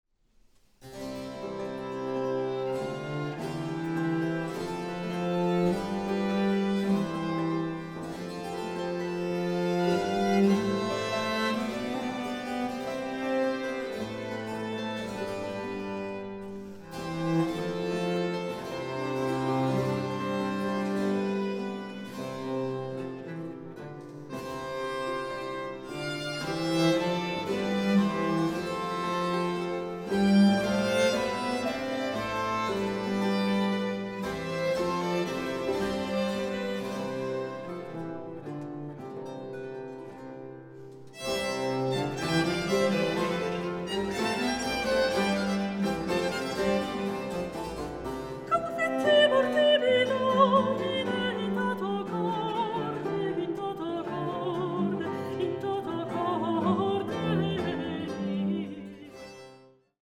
Baroque works on the theme of love in human and divine form
Soprano
The finely balanced ensemble and the agile, expressive voice